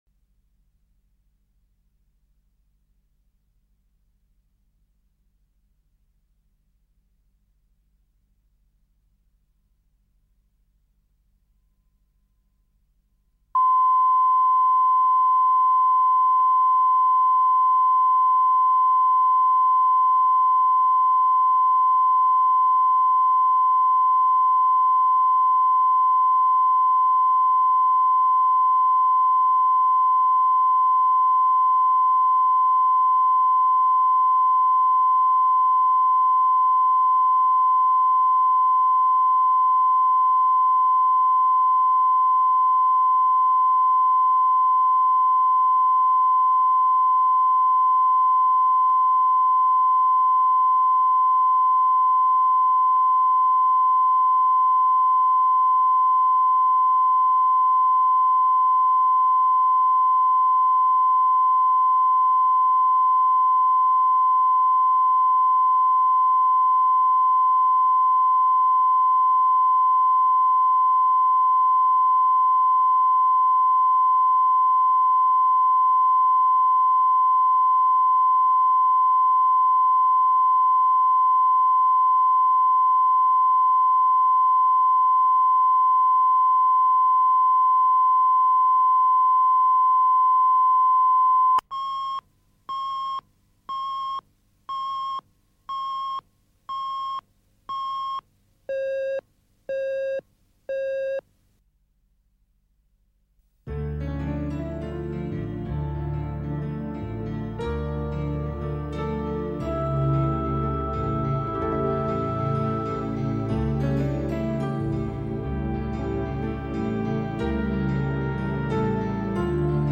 Аудиокнига Барышня и хулиган | Библиотека аудиокниг